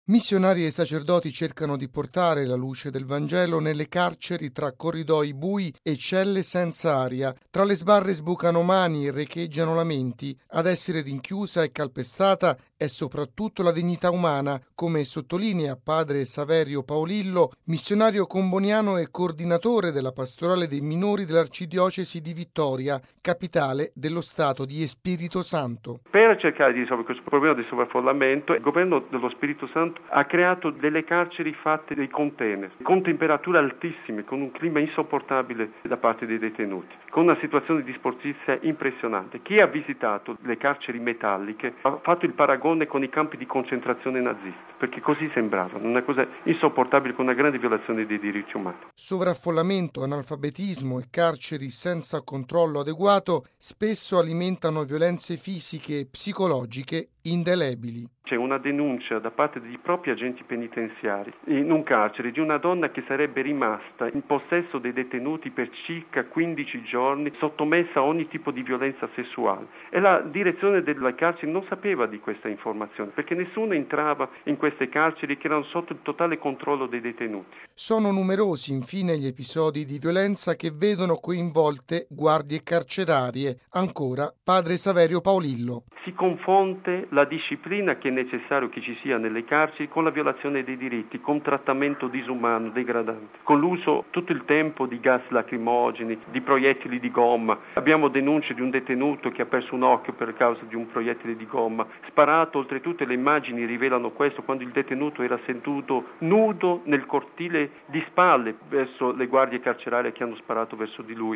Sitema carcerario in Brasile: sovraffollamento e violenze calpestano la dignità umana. Intervista